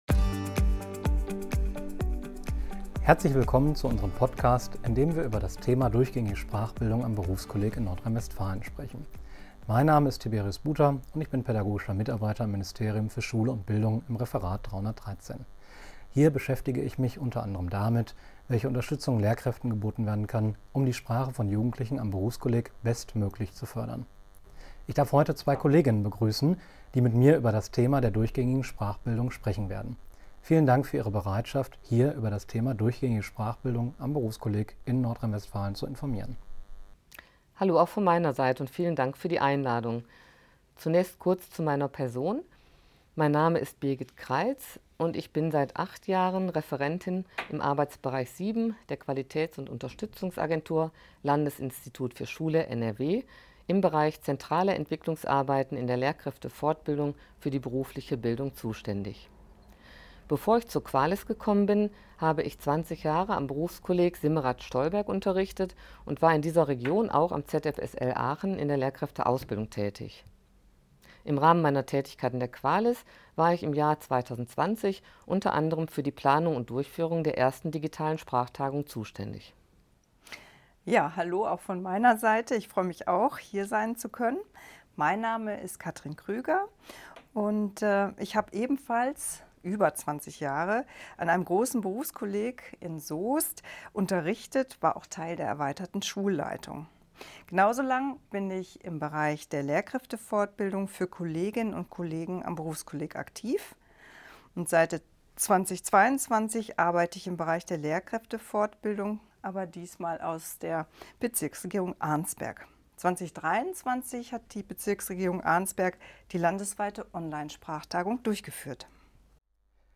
Podcast hören (MP3 30,4 MB), 16:36 min, Aufnahme vom 7.7.2025 im Ministerium für Schule und Bildung NRW.